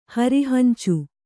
♪ hari hancu